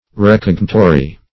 Meaning of recognitory. recognitory synonyms, pronunciation, spelling and more from Free Dictionary.
Search Result for " recognitory" : The Collaborative International Dictionary of English v.0.48: Recognitory \Re*cog"ni*to*ry\ (r[-e]*k[o^]g"n[i^]*t[-o]*r[y^]), a. Pertaining to, or connected with, recognition.